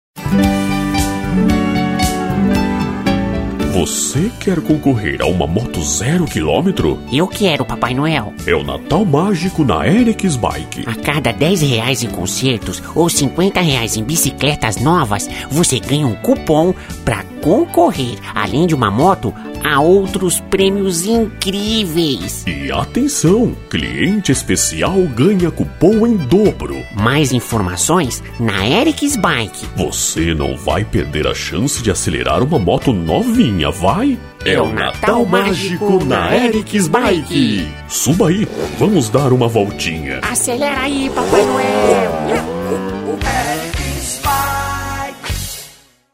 Nossos Jingles